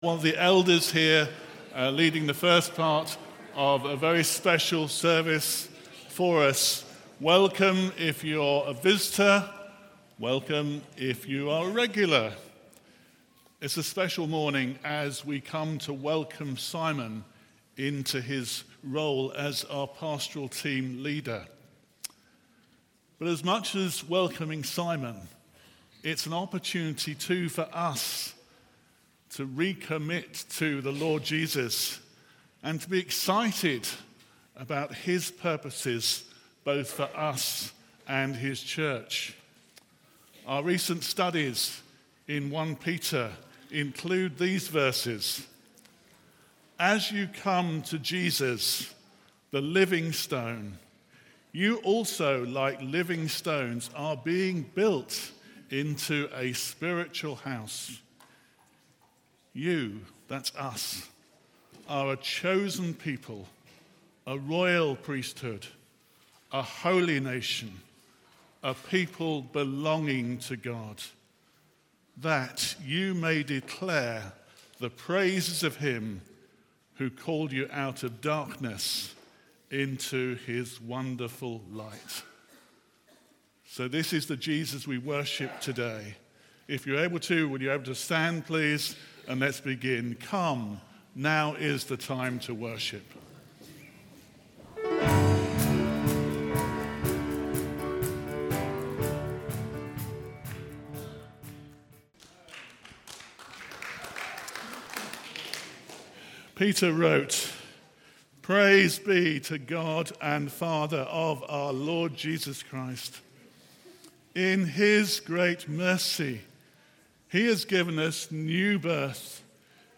Sunday Service
Sermon